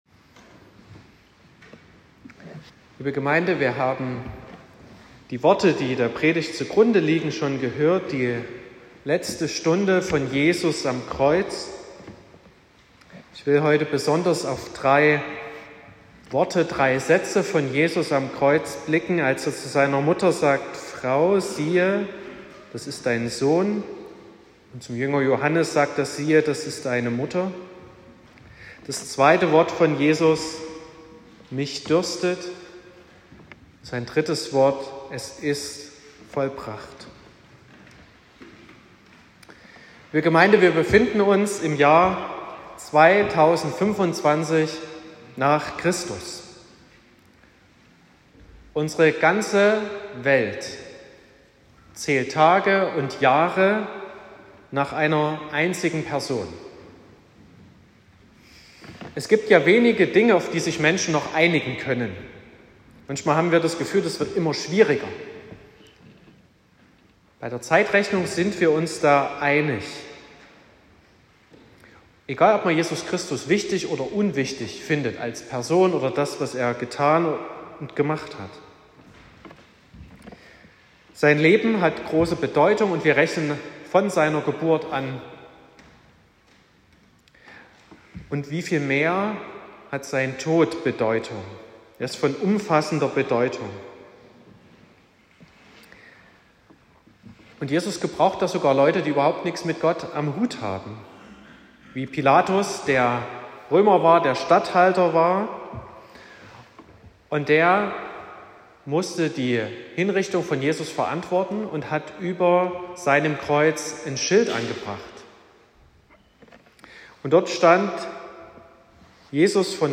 18.04.2025 – Gottesdienst
Predigt (Audio): 2025-04-18_Vollbracht_-_es_ist_an_alles_gedacht_.m4a (8,7 MB)